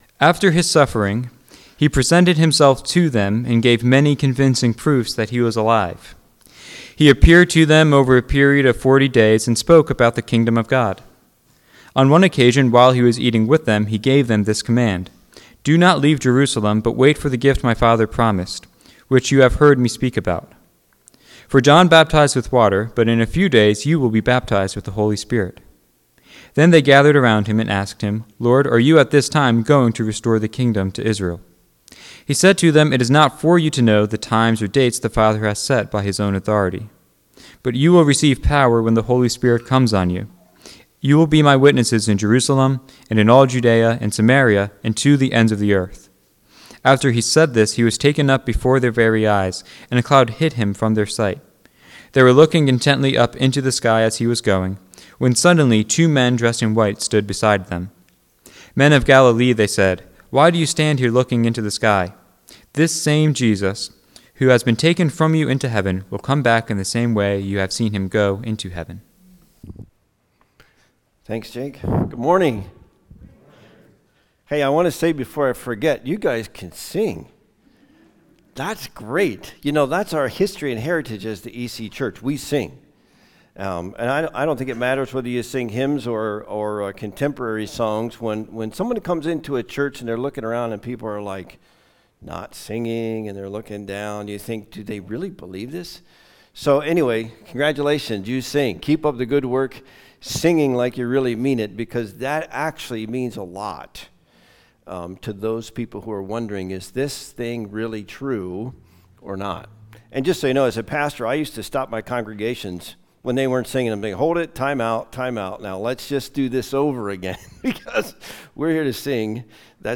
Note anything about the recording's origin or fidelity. Welcome to our collection of sermons graciously shared by guest speakers we have had at Bethel Church of Conestoga!